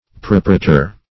Search Result for " propretor" : The Collaborative International Dictionary of English v.0.48: Propretor \Pro*pre"tor\, n. [L. propraetor; pro for, before + praetor a pretor.]